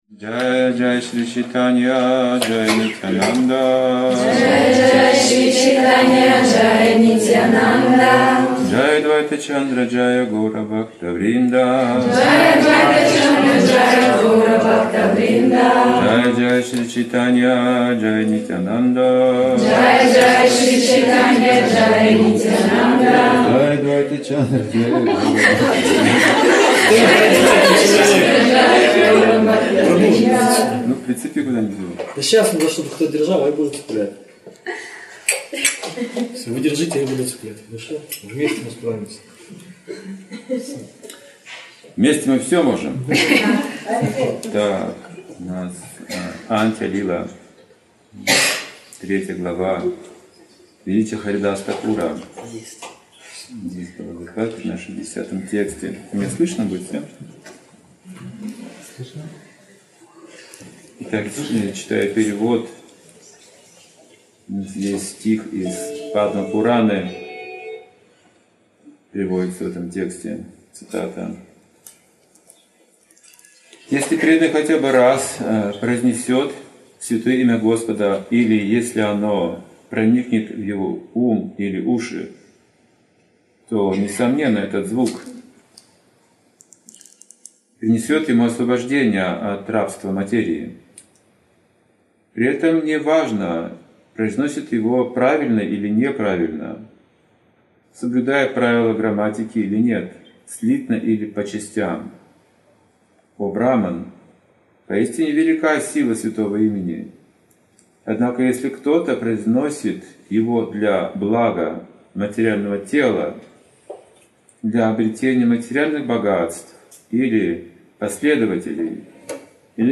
Ответы на вопросы